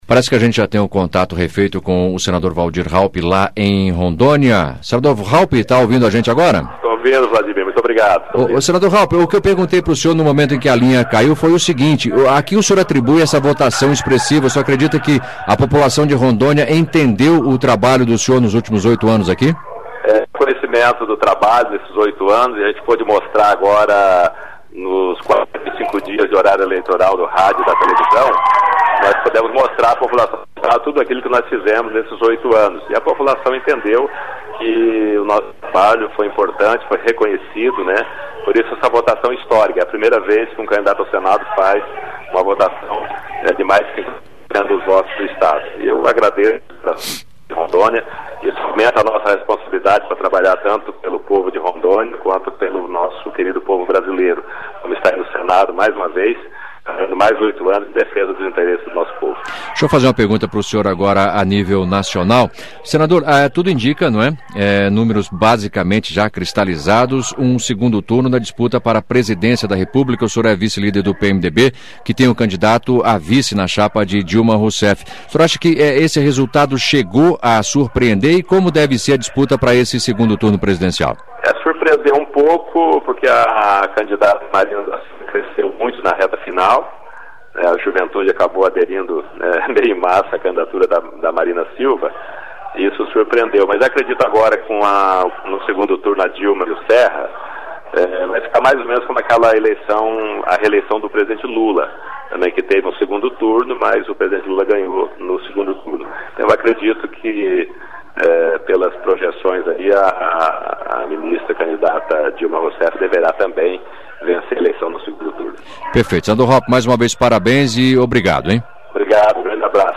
Entrevista com o senador reeleito Valdir Raupp (PMDB-RO)